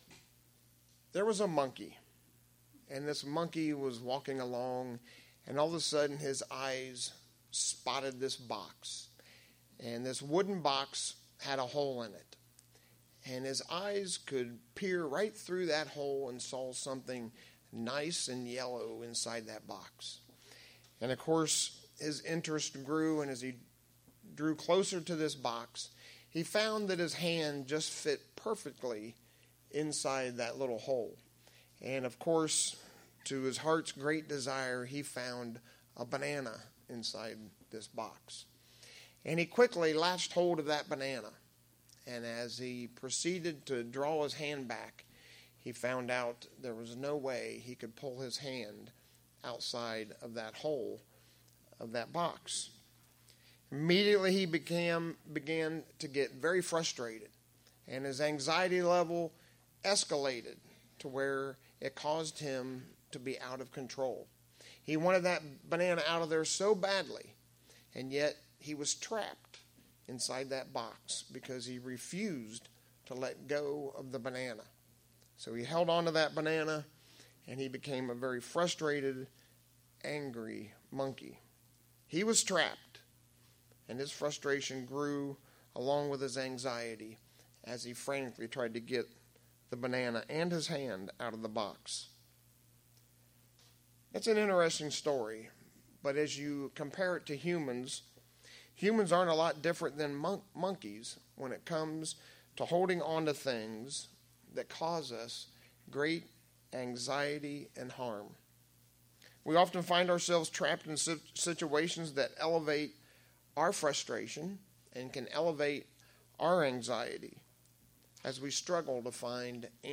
In our sermon today we'll look at 3 very important things that could show-up in our life and when they do, we need to remember to let them go.
Given in Lehigh Valley, PA